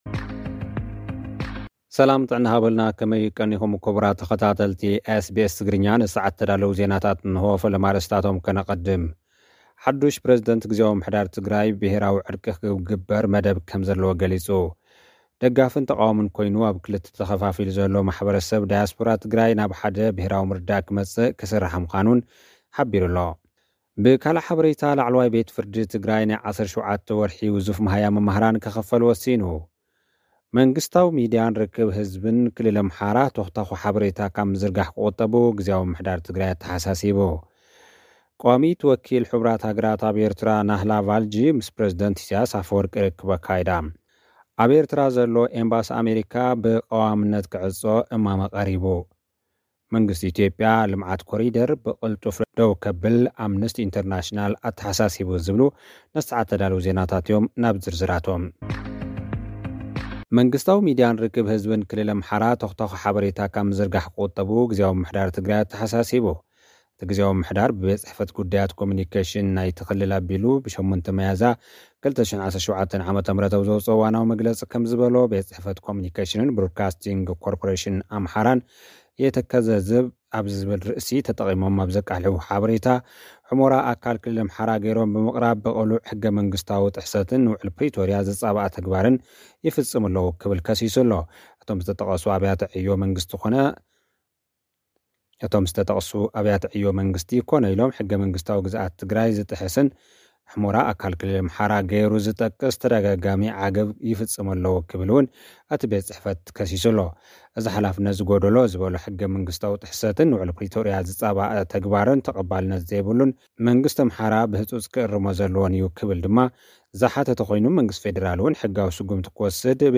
ኣብ ኤርትራ ዘሎ፡ ኤምባሲ ኣመሪካ ንሓዋሩ ክዕጾ እማመ ቀሪቡ። (ጸብጻብ)
ልኡኽና ዝሰደደልና ጸብጻብ እዞም ዝስዕቡ ኣርእስታት ኣለዉዎ፡ ሓዱሽ ፕረዚደንት ግዝያዊ ምምሕዳር ትግራይ ብሄራዊ ዕርቂ ክግበር መደብ ከም ዘሎ ገሊጹ።